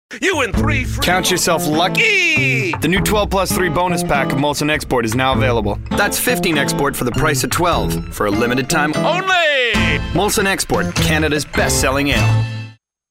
Publicité (Molson) ANG